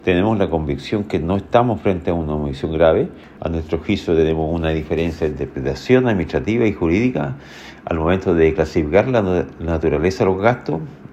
El gobernador de O’Higgins y presidente de la Asociación de Gobernadores, Pablo Silva, afirmó que tienen la convicción de que no están frente a una omisión grave.